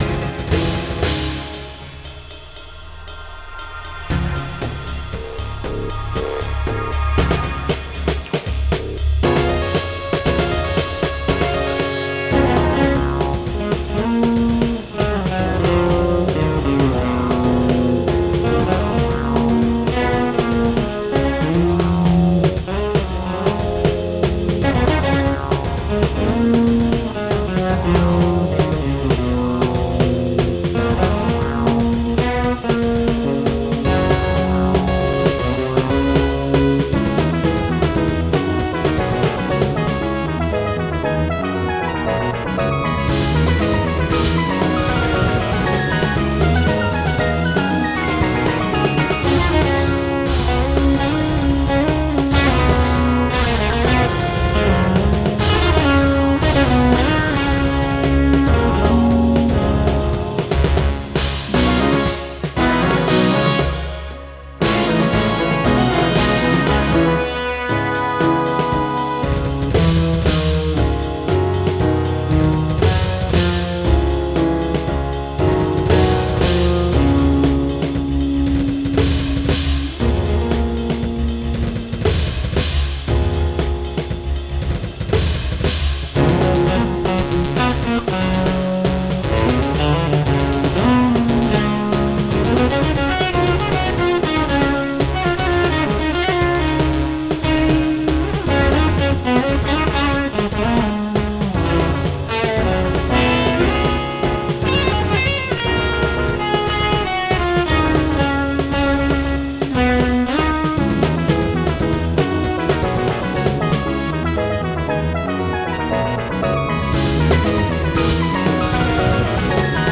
Electronic, Jazz (2023)